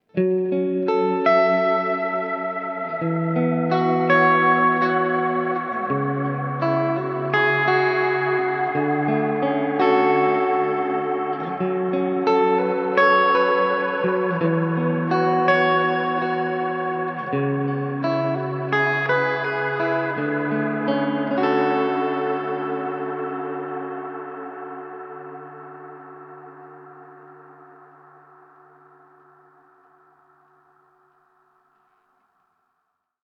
Mit Variation C bekommst Du schließlich noch einen Hall, der sich sehr gut für lange atmosphärische Hallklänge eignet. Insbesondere, wenn Du noch etwas Modulation hinzufügst, führt der Klang in träumerische Sphären.
Hall 224 (C) Dreamy Ambient